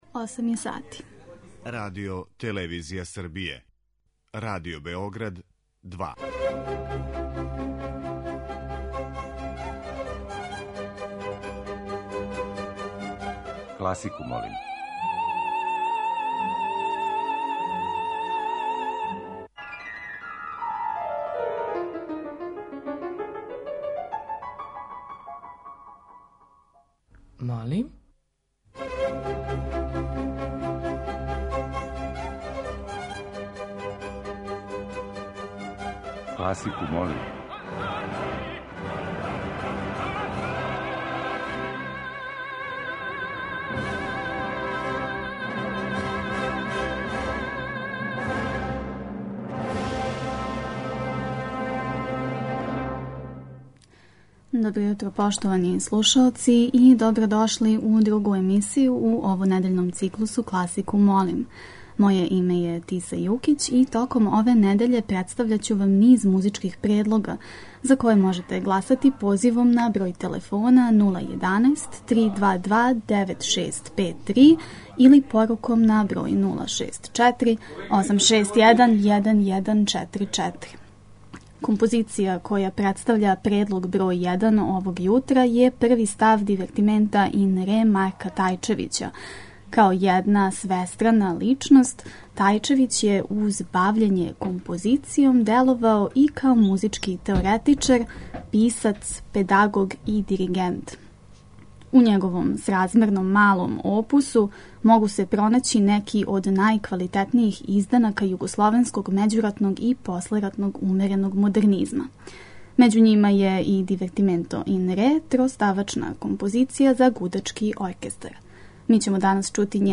Тема циклуса биће музика за клавир америчког романтизма, конкретно композиције Друге новоенглеске школе.